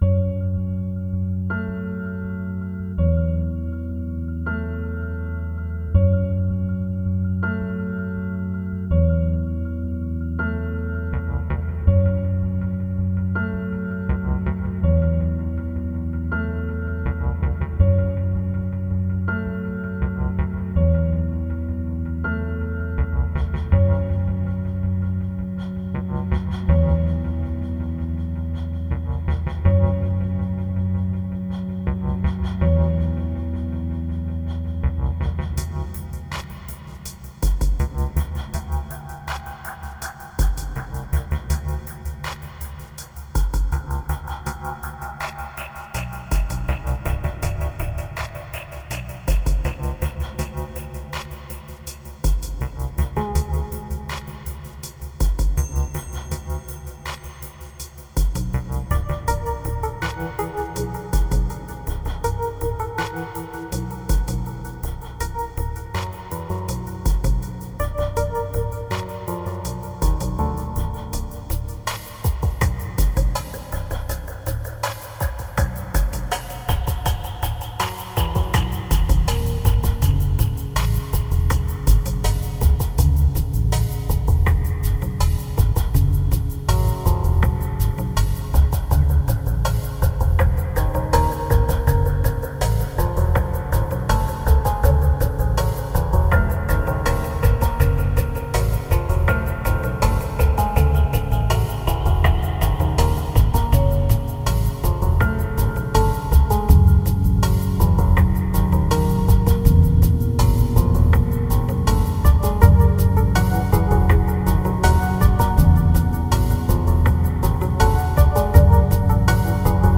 2000📈 - 50%🤔 - 81BPM🔊 - 2012-08-25📅 - 24🌟